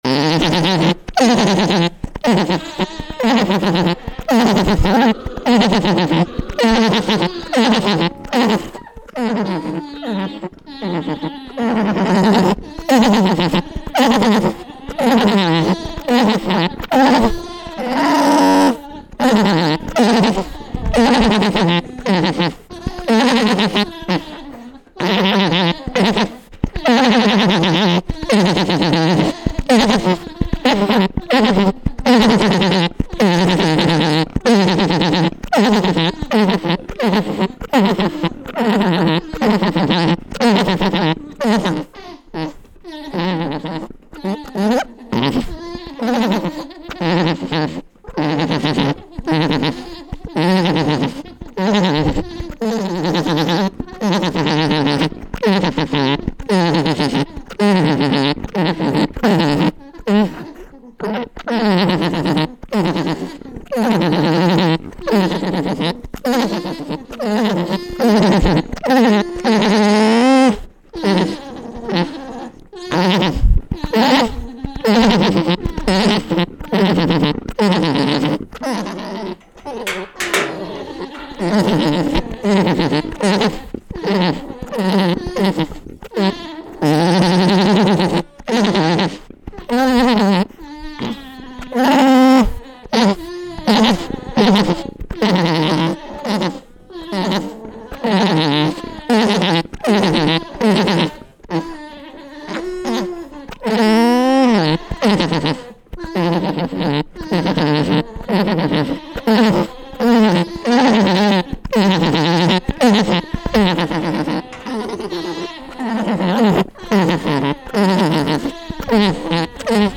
Fancy That Appaloosa Skydance - Alpaca Orgling Mating Sound
The male “orgles” or sings to the female during the act of matin, which also encourages ovulation.
MALE_ALPACA_ORGLING-.mp3